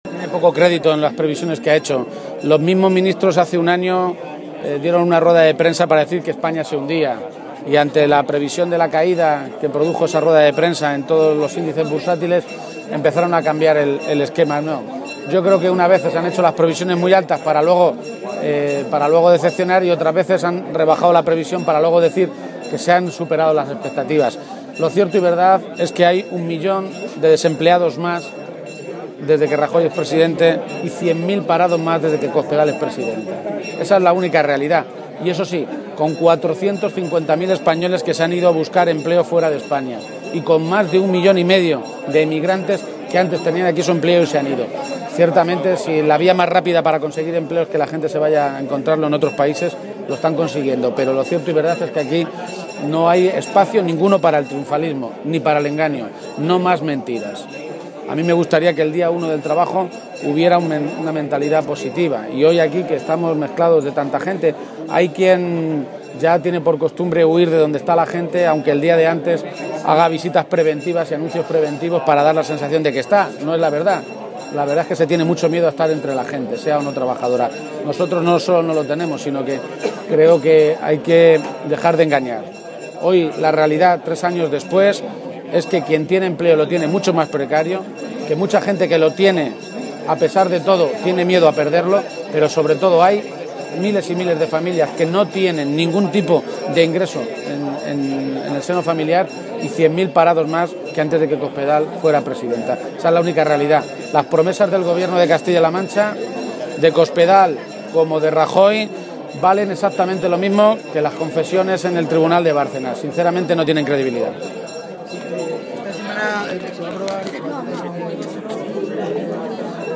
García-Page se pronunciaba de esta manera en declaraciones a los medios de comunicación durante la tradicional y toledana Romería del Valle.
Cortes de audio de la rueda de prensa